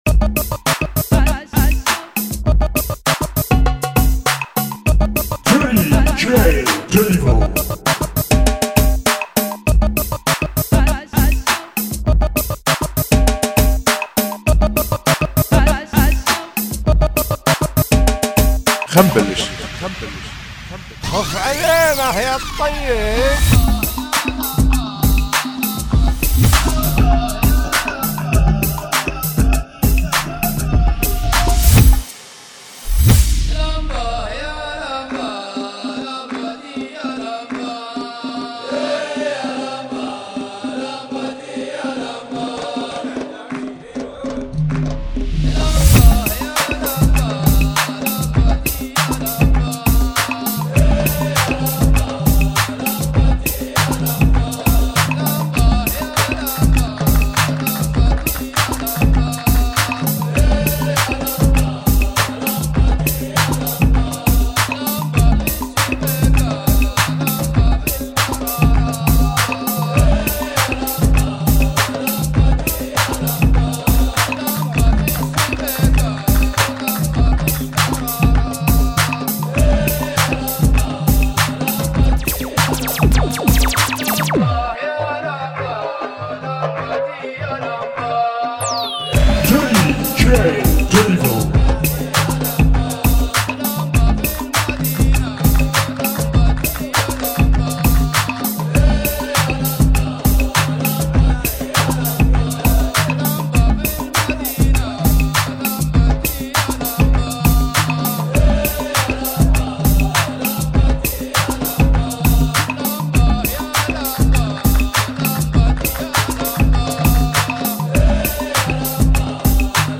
ريمكسي